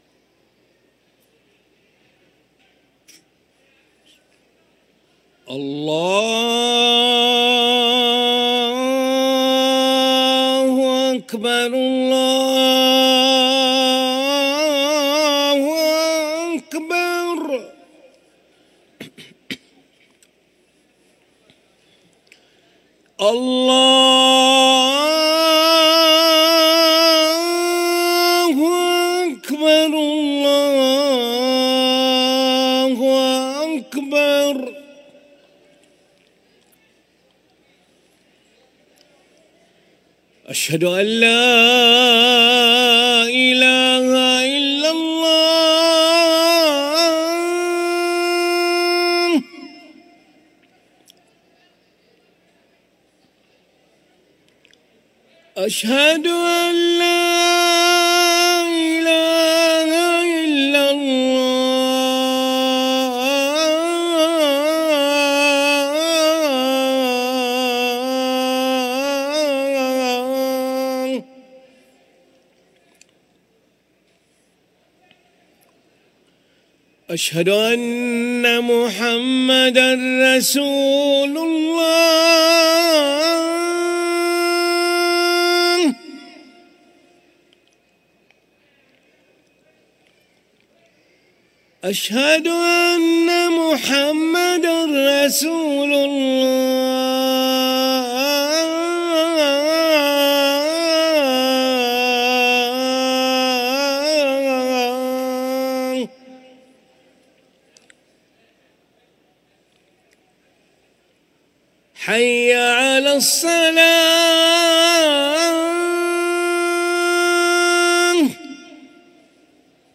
أذان العشاء للمؤذن علي ملا الخميس 2 جمادى الأولى 1445هـ > ١٤٤٥ 🕋 > ركن الأذان 🕋 > المزيد - تلاوات الحرمين